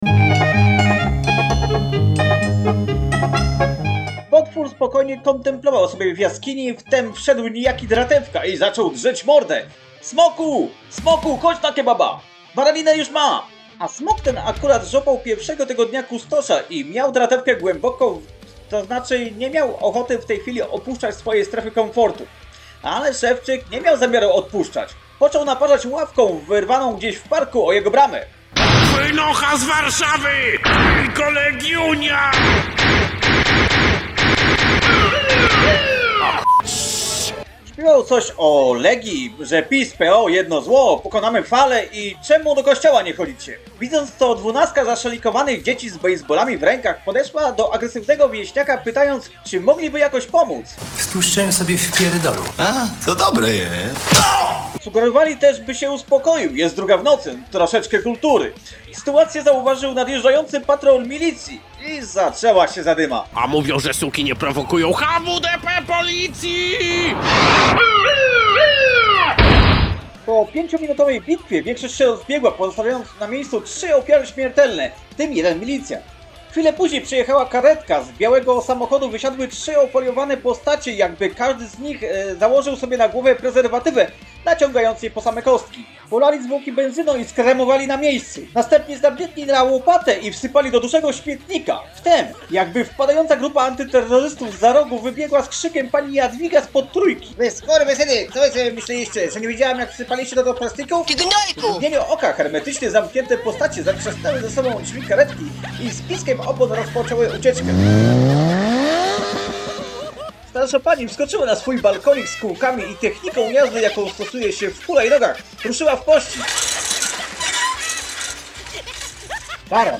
Czytał sam